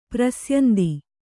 ♪ prasyandi